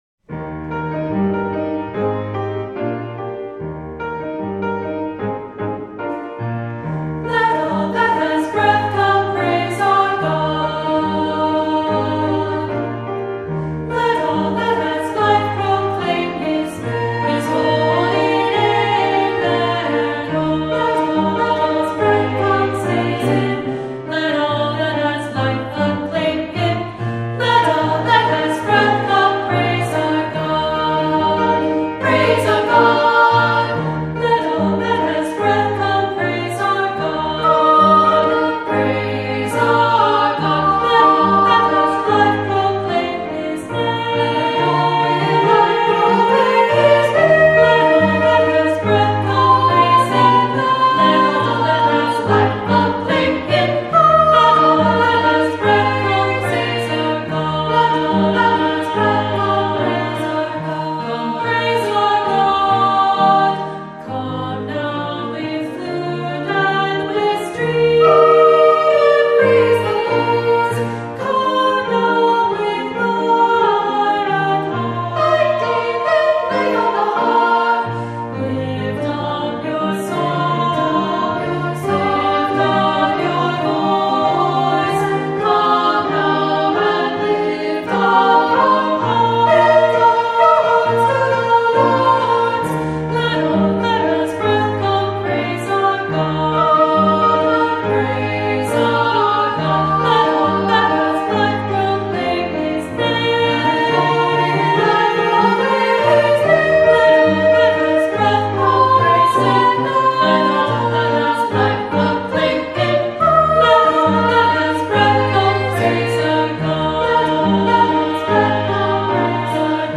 Voicing: 2-part Children's Choir - SA,2-part Treble Choir